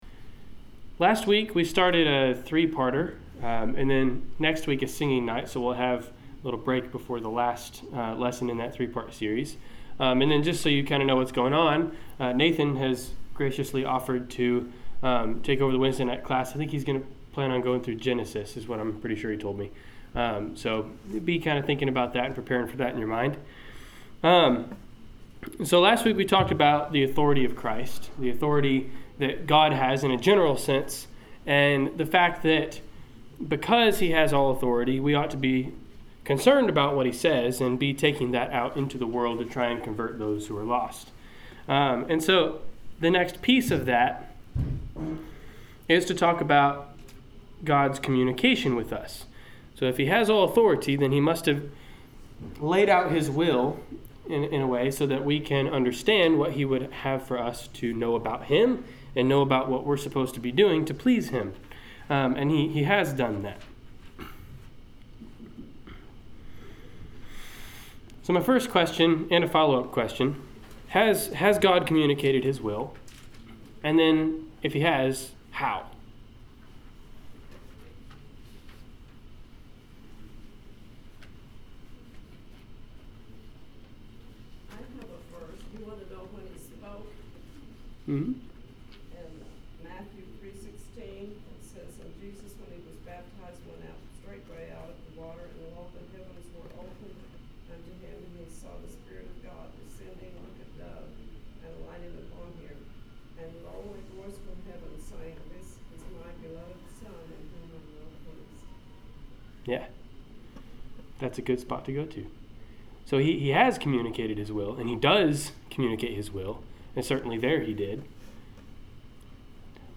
Service Type: Wednesday Night Class